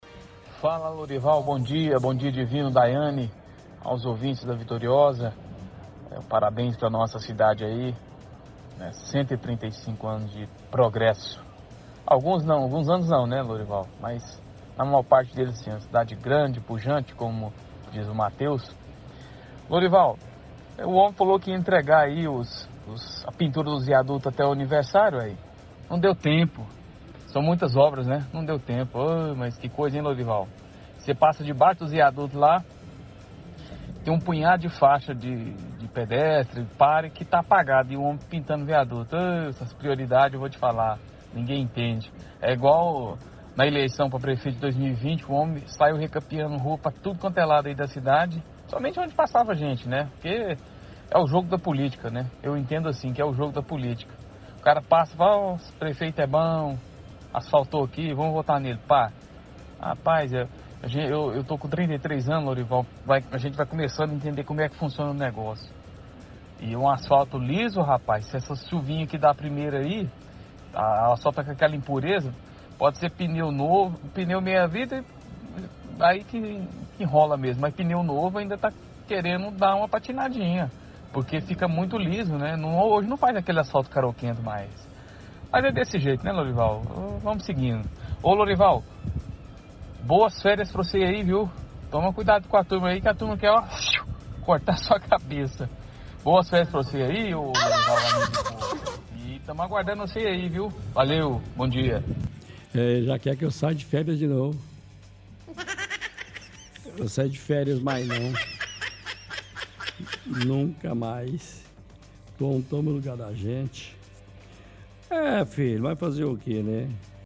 – Ouvinte reclama da limpeza dos viadutos, fala que prefeito tinha prometido entregar os serviços até o aniversário da cidade, porém “são muitas obras ne?”.